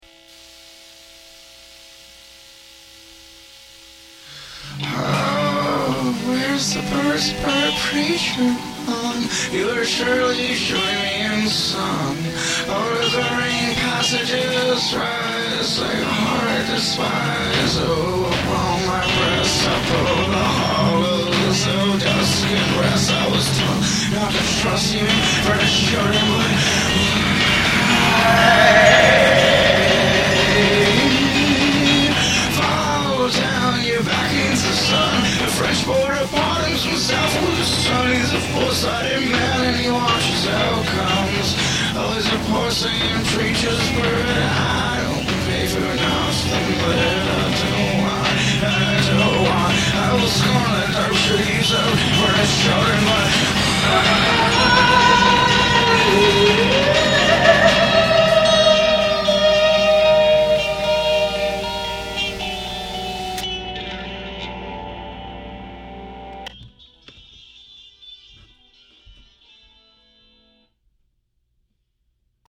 " which could plausibly be a demo: